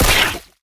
spitter-death-5.ogg